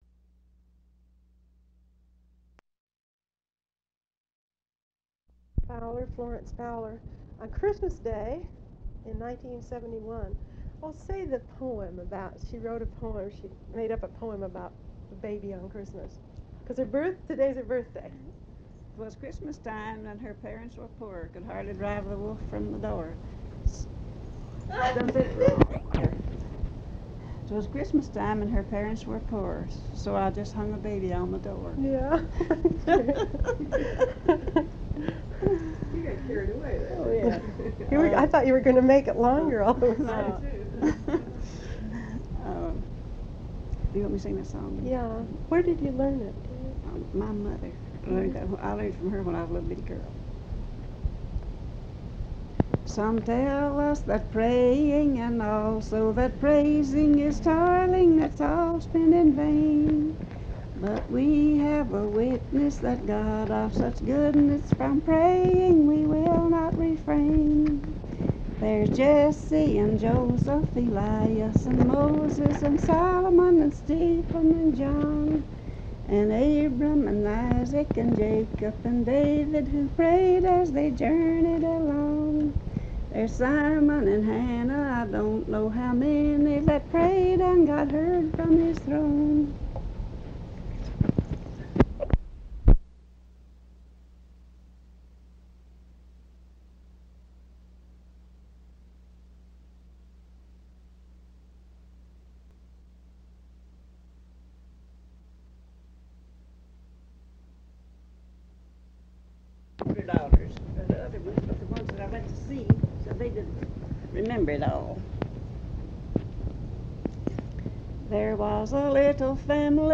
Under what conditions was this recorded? Recording Session